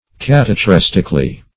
[1913 Webster] -- Cat`a*chres"tic*al*ly, adv.